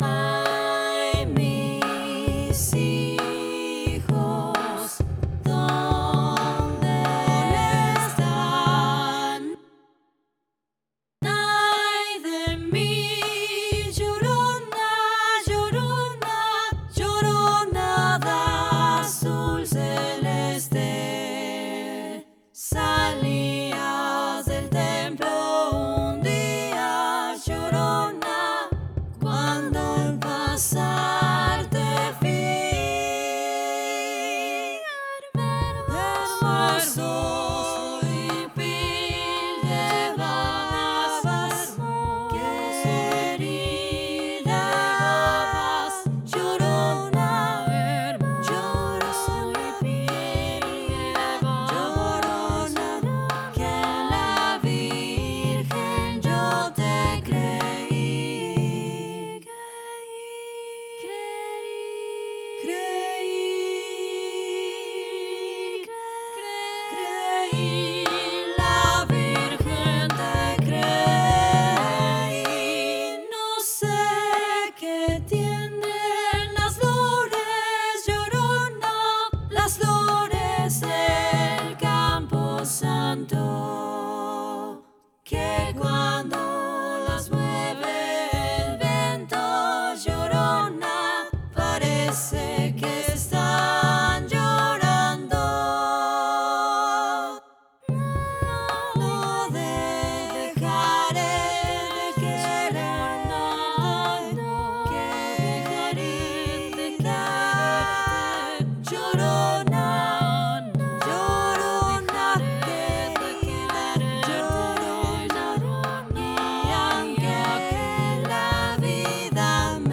SSAA + opt. perc3’00”
*MIDI rendering.
SSAA, opt. perc.